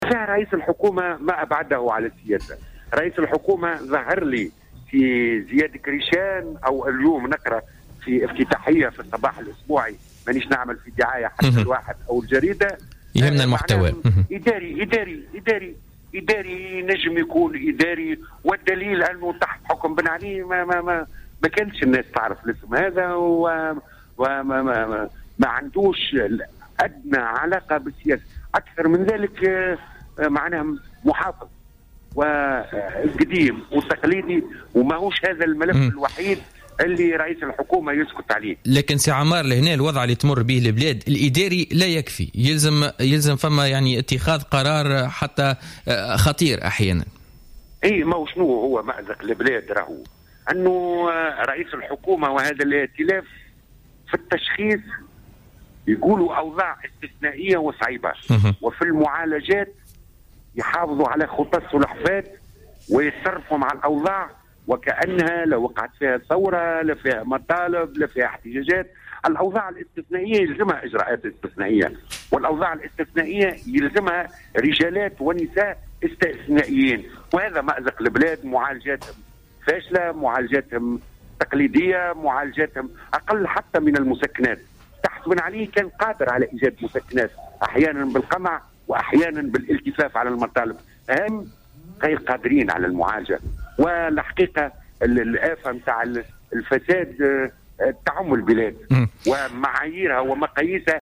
توجه النائب بمجلس نواب الشعب عن الجبهة الشعبية عمار عمروسية في تصريح للجوهرة أف أم في برنامج بوليتكا لليوم الاثنين 18 أفريل 2016 لرئيس الحكومة الحبيب الصيد بنقد لاذع وصف فيه بالـ "ڨديم" والتقليدي" وفق تعبيره.